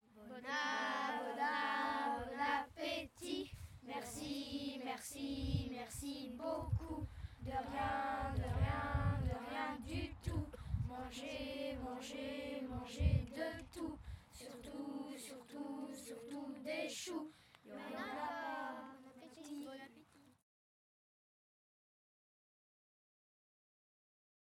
Genre : chant
Type : chant de mouvement de jeunesse
Interprète(s) : Patro de Pontaury
Lieu d'enregistrement : Florennes
Chanté avant le repas.